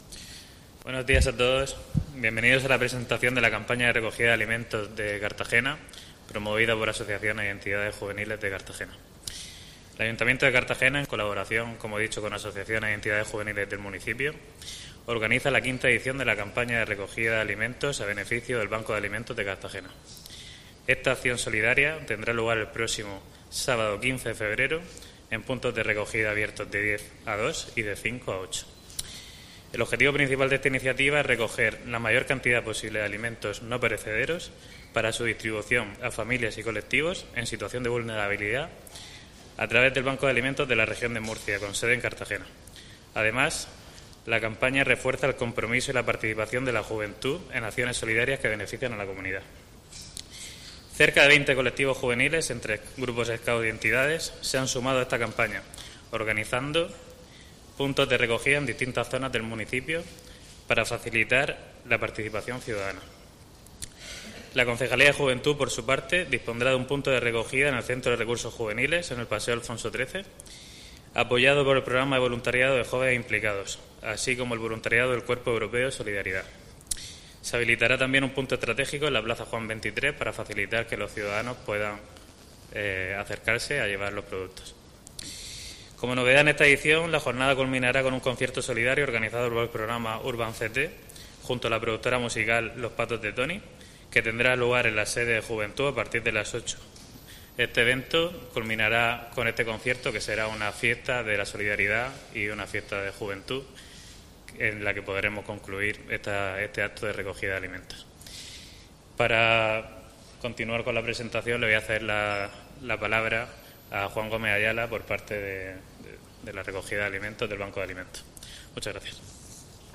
Enlace a Presentación de la V Campaña de Recogida de Alimentos de Juventud